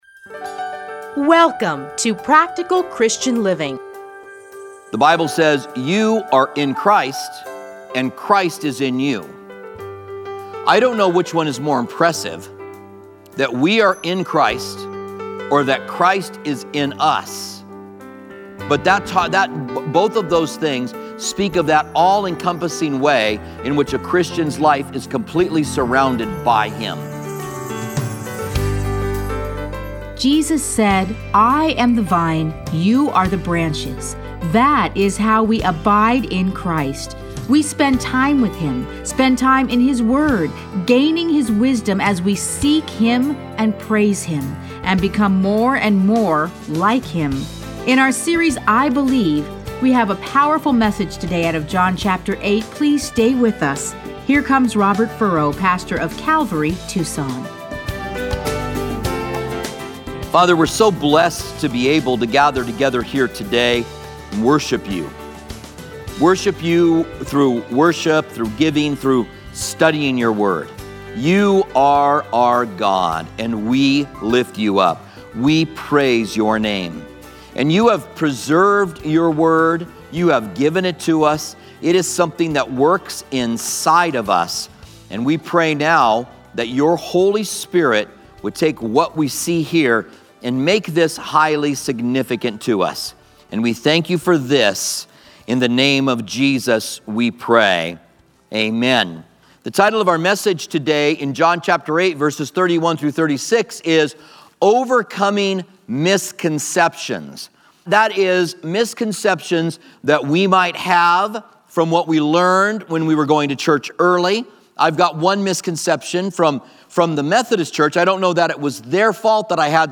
Listen to a teaching from John 8:37-59.